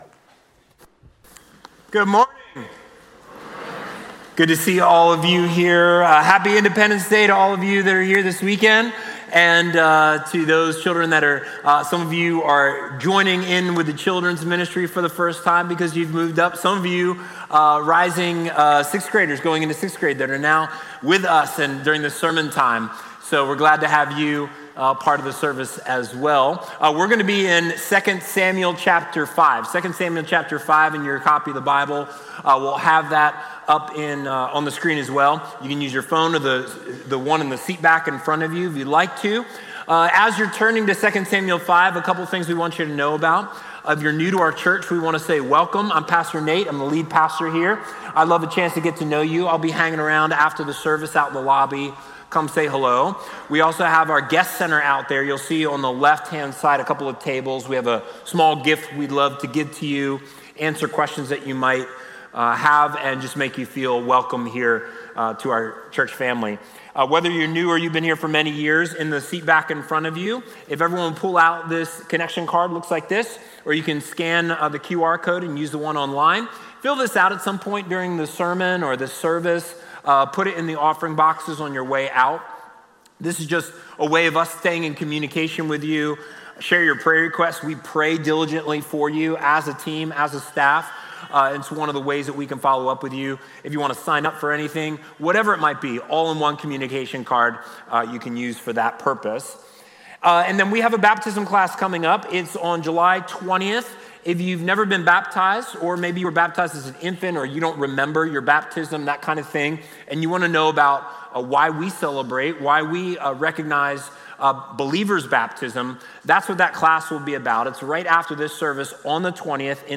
Watch previously recorded Sunday sermons.
This is a Communion Sunday.